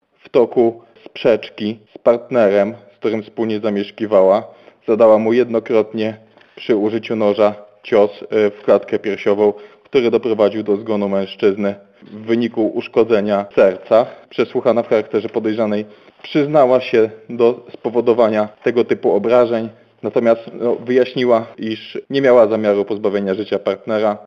O szczegółach mówi prokurator rejonowy w Lubartowie Krzysztof Sokół.